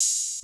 [open hat] tm88.wav